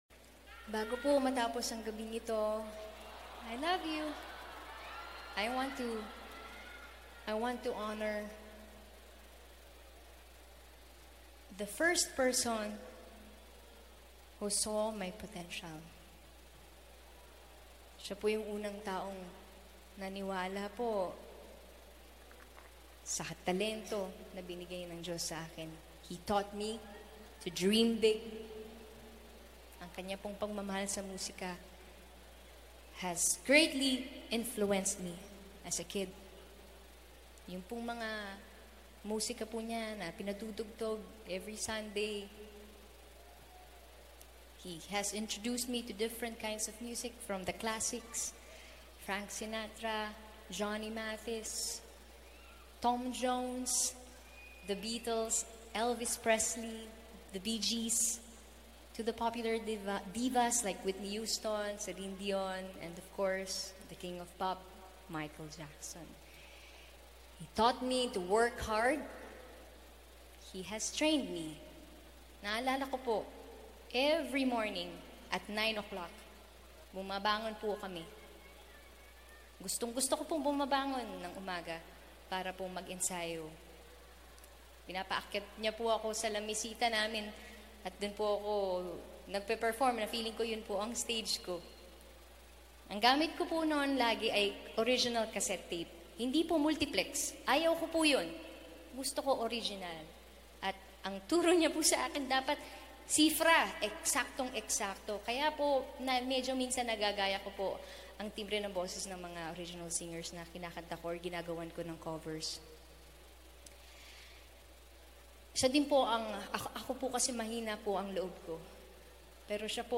20th Anniversary Concert